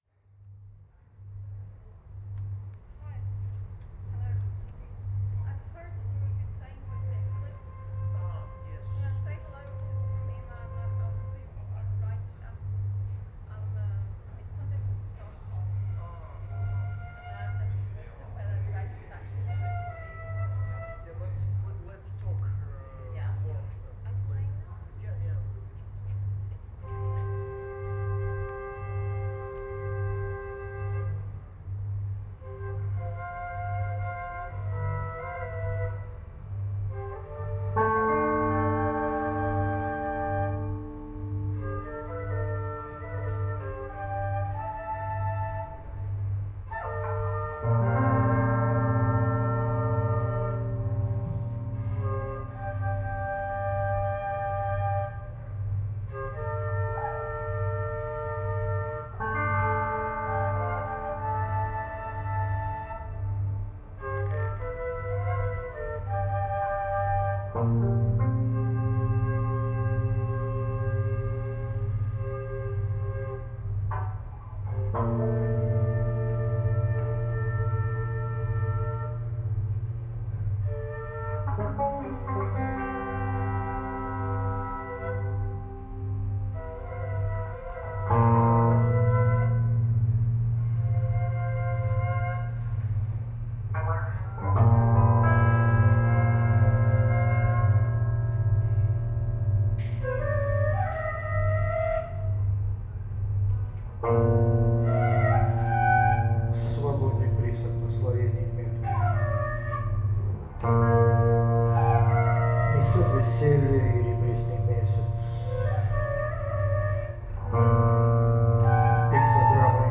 Beyond free rock, noise rock & avant rock!!
voice, guitars, harmonica, electronics
voice, drums, contact mics, effects